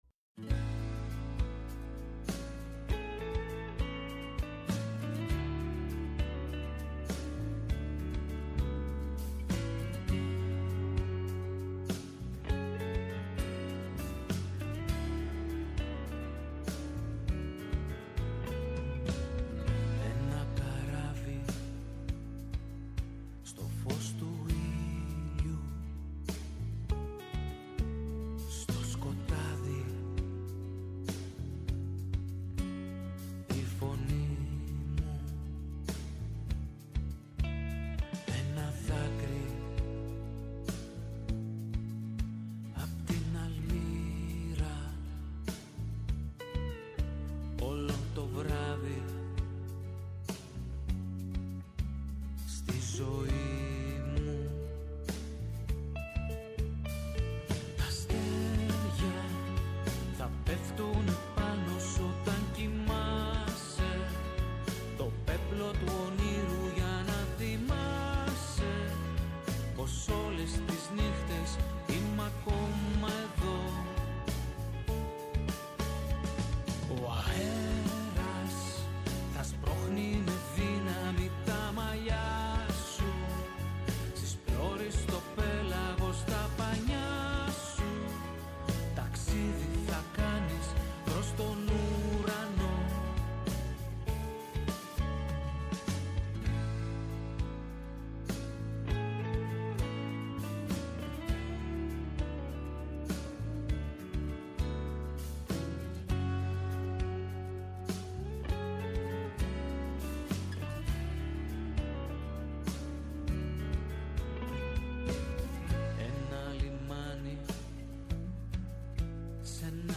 ΔΕΥΤΕΡΟ ΠΡΟΓΡΑΜΜΑ Αφιερώματα Μουσική Συνεντεύξεις